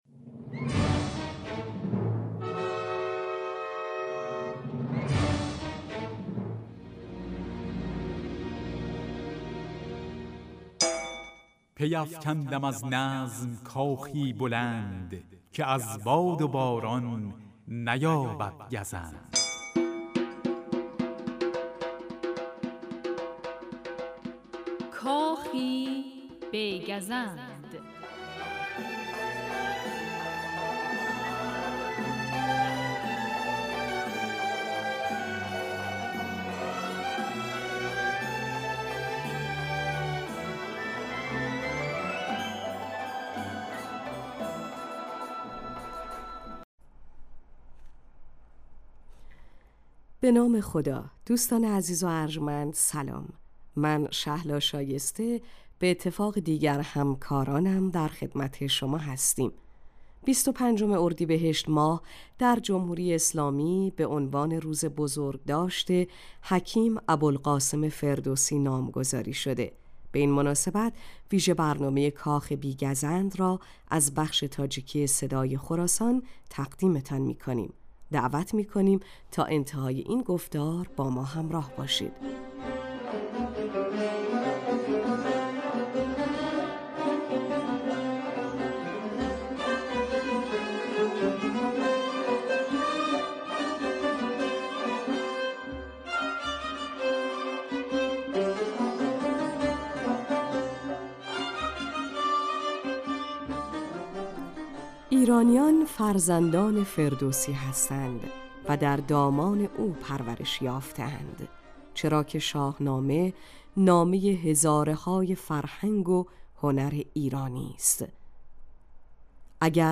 Кохи бегазанд вижа барномае аст, ки дар радиои тоҷикӣ ба муносибати солрӯзи бузургдошти ҳаким Абулқосими Фирдавсӣ дар 15 май таҳия шудааст.